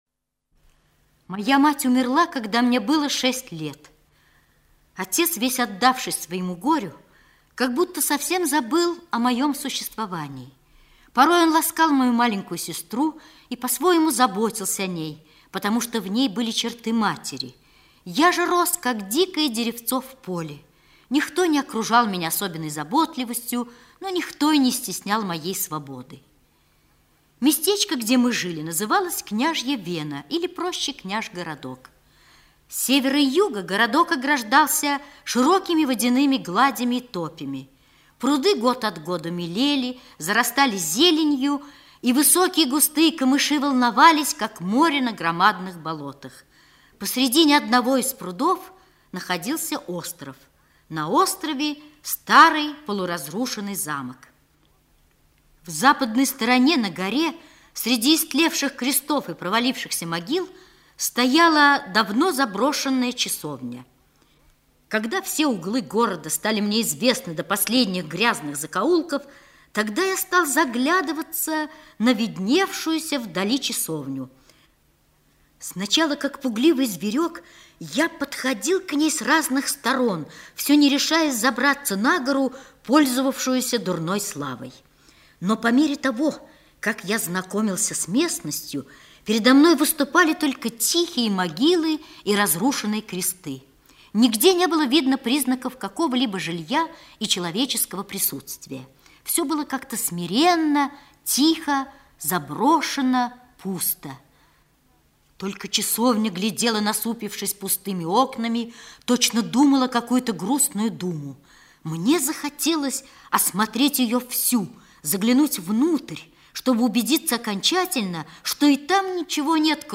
В дурном обществе - аудио рассказ Короленко В.Г. Рассказ про мальчика Васю, который в шесть лет потерял маму.